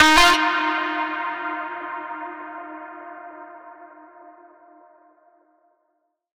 VR_vox_hit_ahai_F.wav